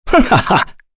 Kain-Vox_Attack5.wav